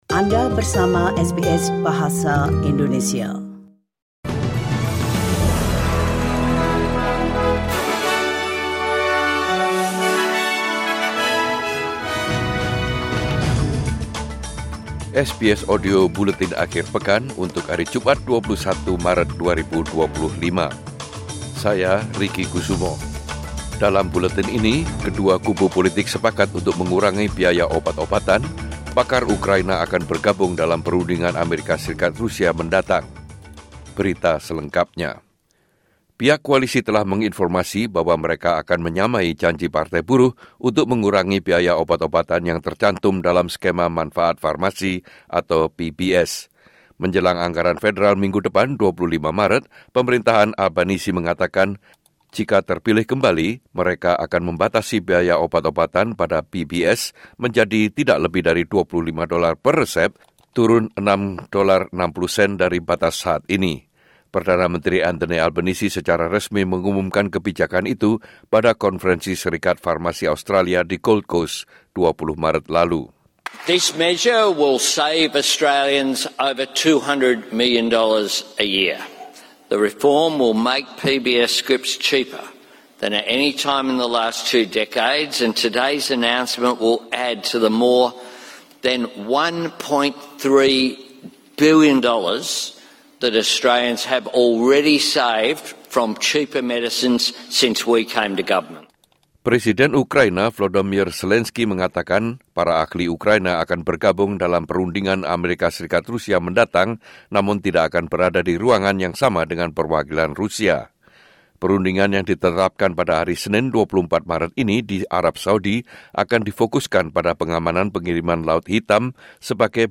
Rangkuman Berita Mingguan SBS Audio Program Bahasa Indonesia - 21 Maret 2025